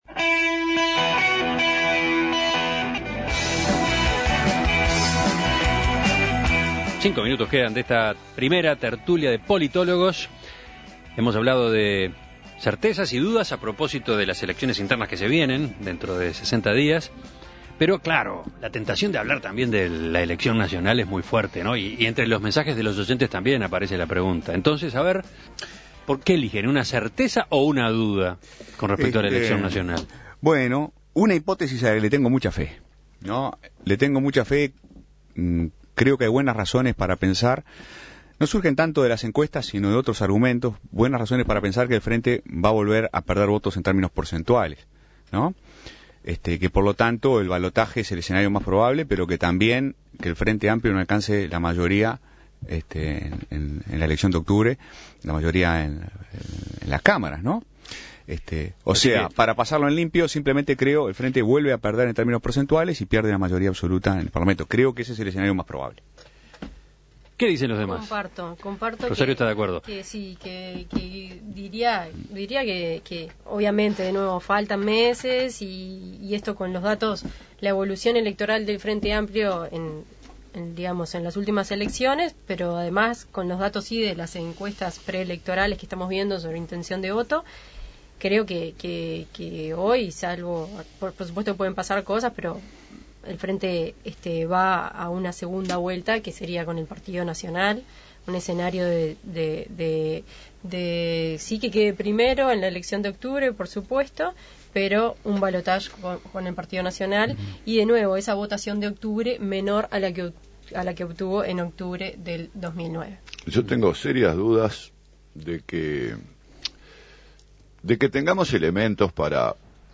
Tertulia de politólogos: Certezas y dudas a propósito de las elecciones internas